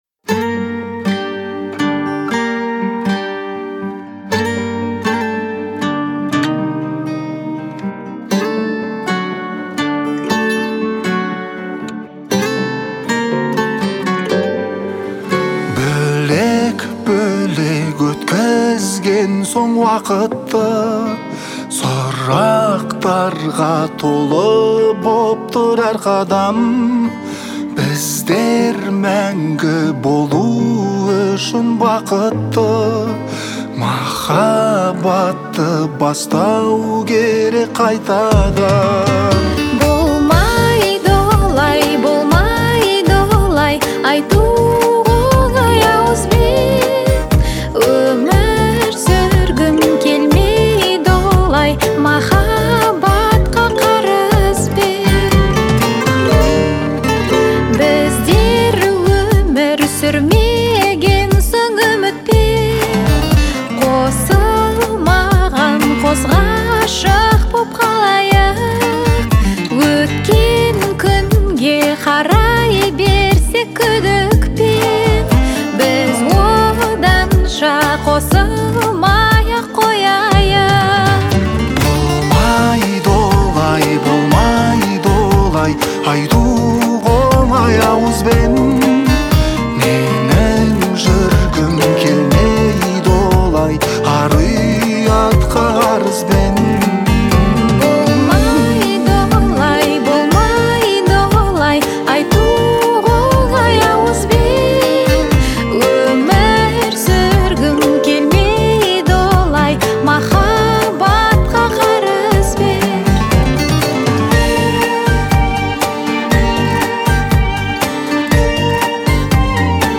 это яркая и эмоциональная композиция в жанре поп